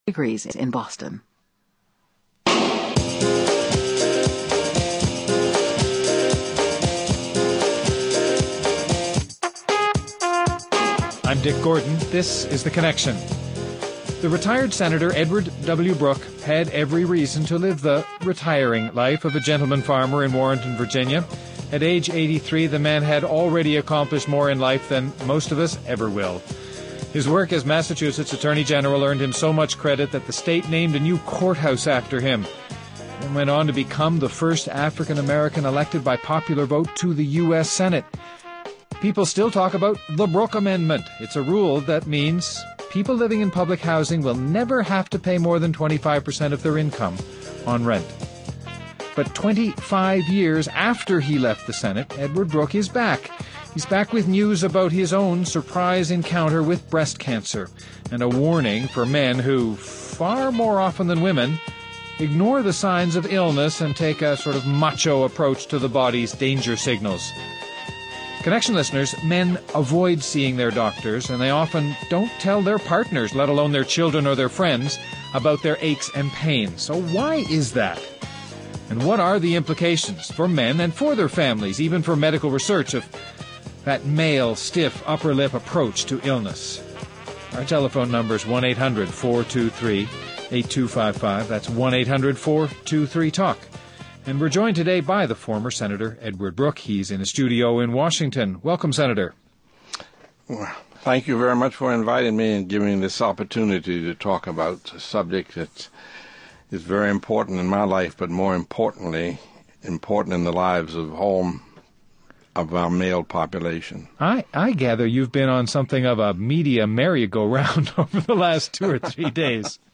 Guests: Former Senator Edward Brooke (R-MA)